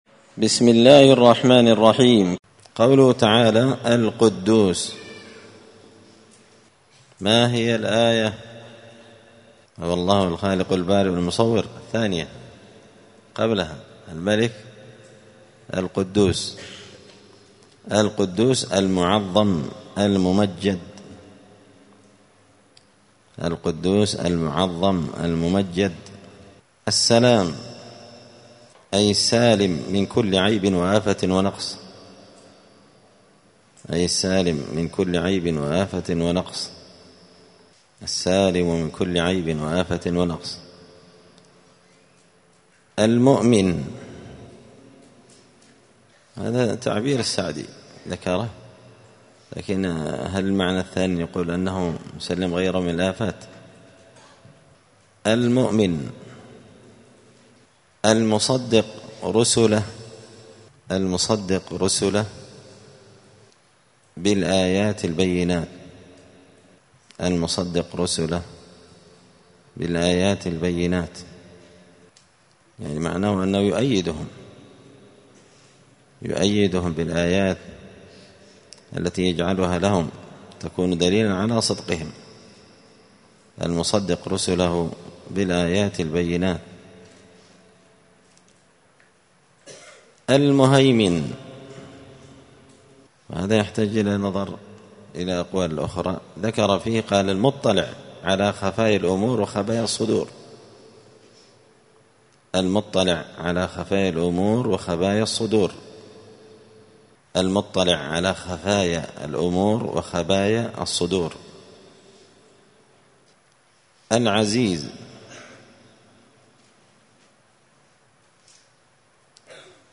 الأثنين 27 جمادى الأولى 1445 هــــ | الدروس، دروس القران وعلومة، زبدة الأقوال في غريب كلام المتعال | شارك بتعليقك | 71 المشاهدات